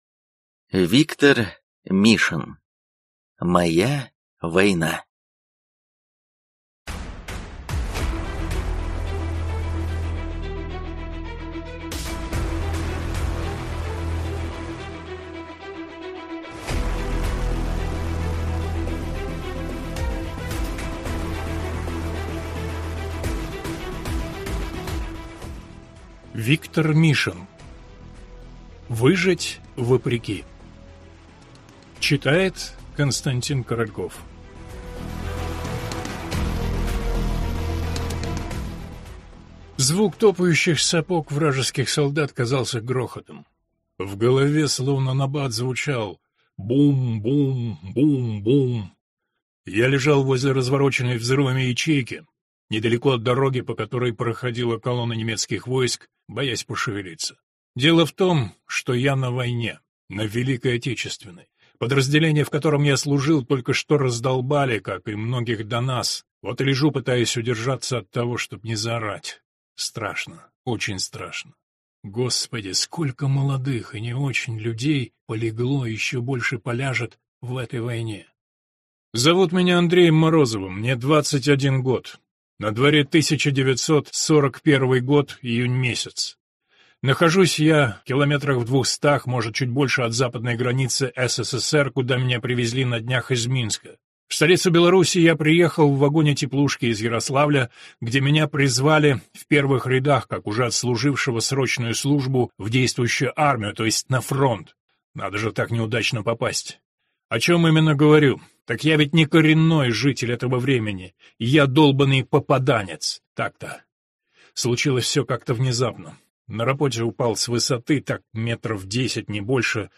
Виктор Мишин. Моя война. Аудиокнига.